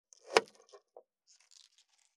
540切る,包丁,厨房,台所,野菜切る,咀嚼音,ナイフ,調理音,まな板
効果音